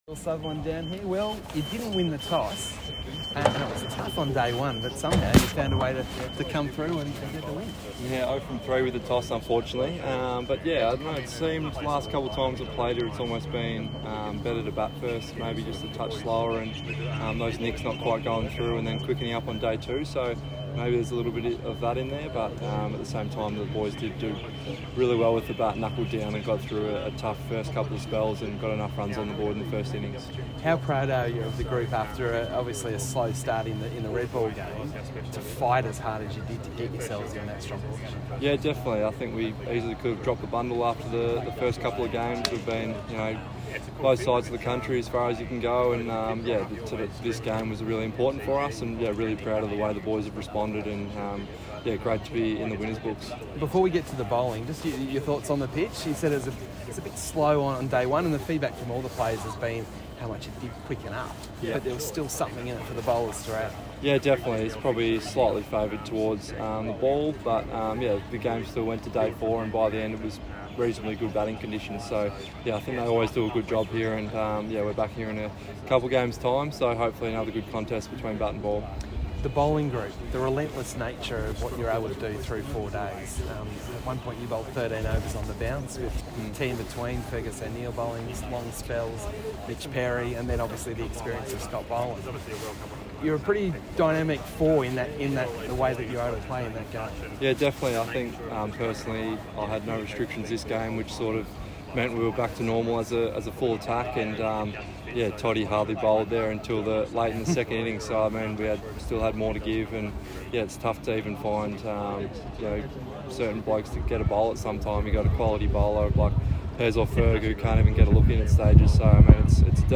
Victoria captain Will Sutherland speaks following Victoria’s 205-run win against New South Wales